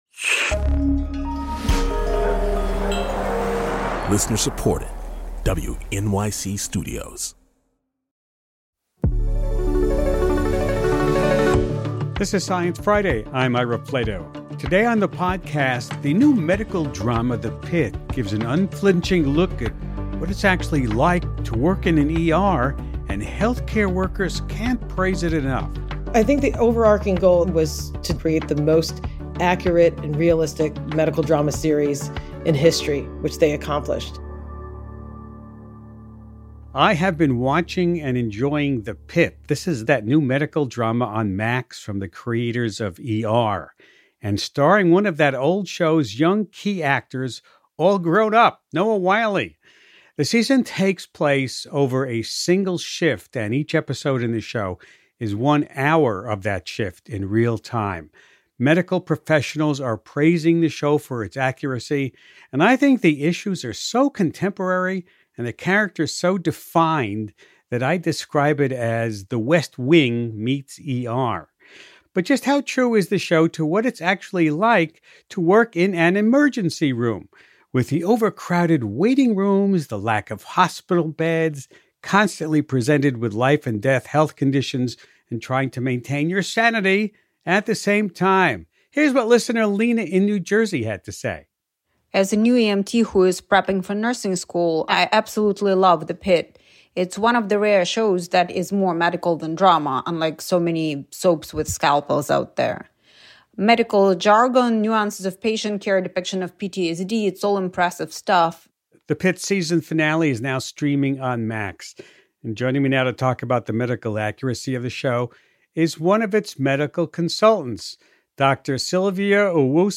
Joining Host Ira Flatow to talk about the accuracy of the show is one of its medical consultants